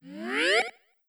GoSFX.wav